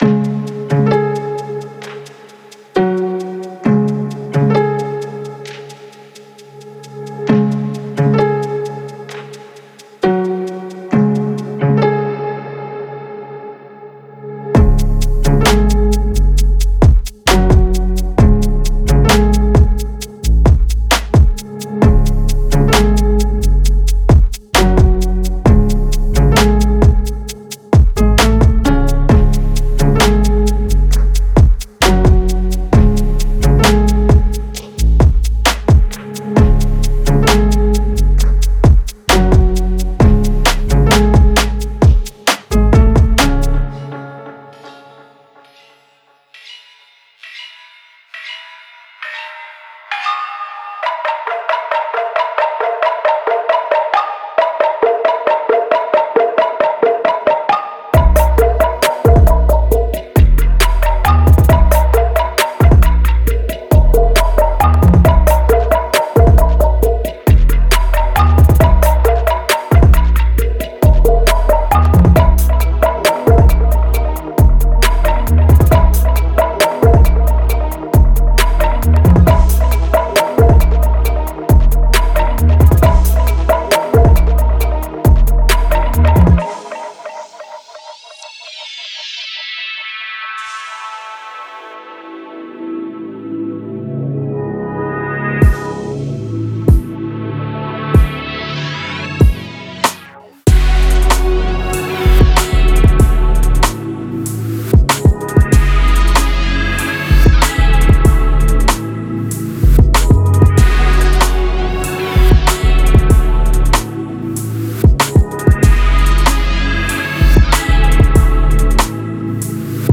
Genre:Trap
このコレクションは、ダークでパワフルなトラッププロダクションをゼロから構築するためのすべてのツールを提供します。
デモサウンドはコチラ↓